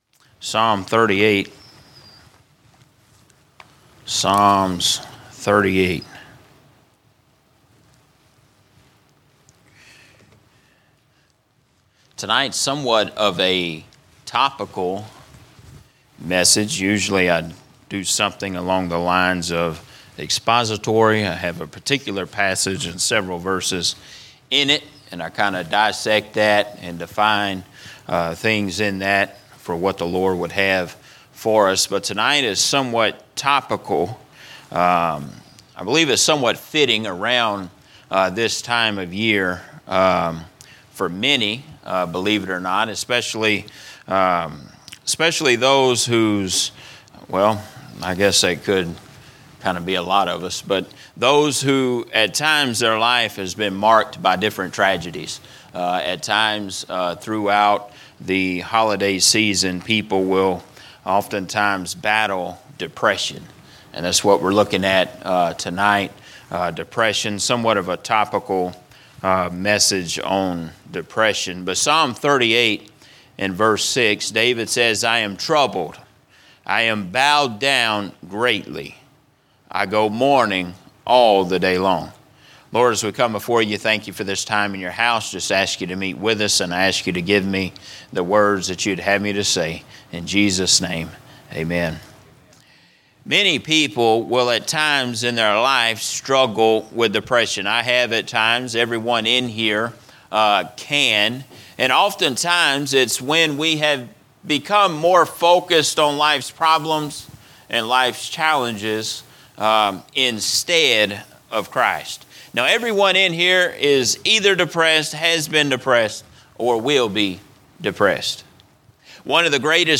From Series: "General Preaching"